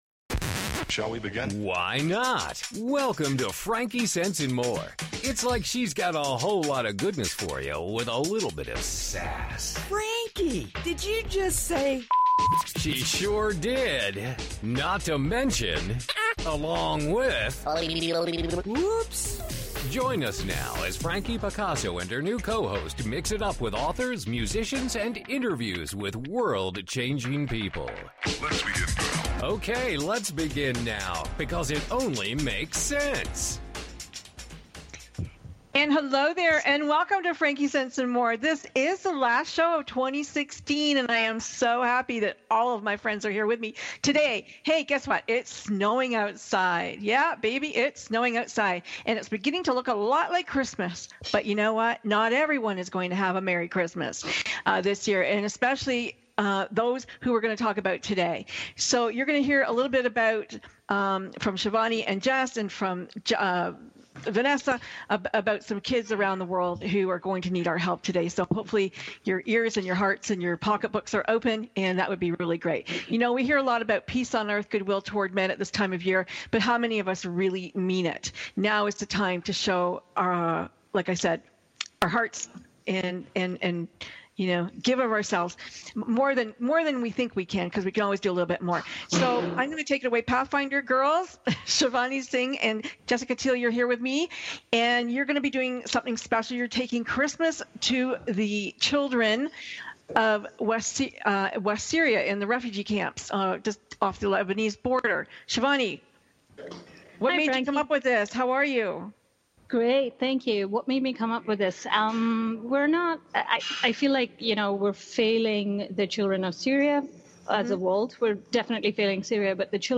PATHFINDER was on the radio to discuss our campaign and mission to raise funds for crucial winter necessities for children in refugee camps along the Syrian Border.
radio-chat-about-pathfinders-campaign.m4a